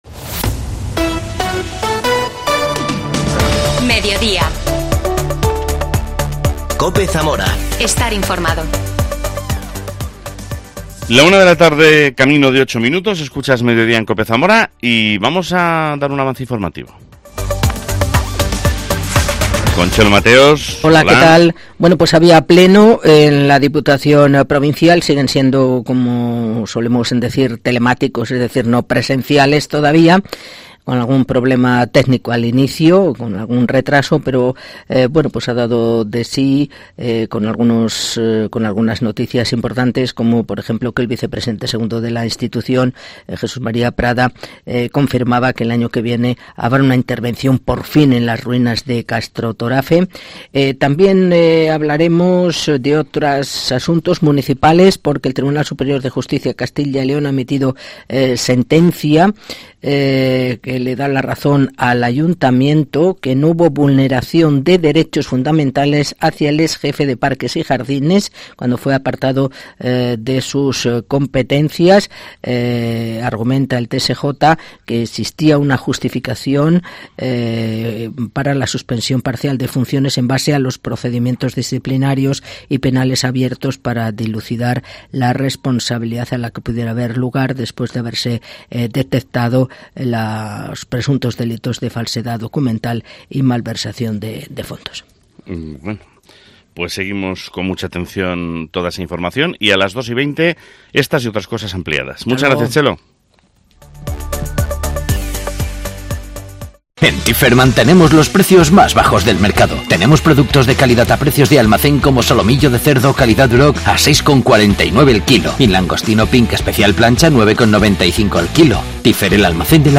AUDIO: Hablamos con Felipe Lubián, alcalde de Lubián, sobre el premio Cultura, que la Fundación Caja Rural de Zamora ha concedido a la Banda de...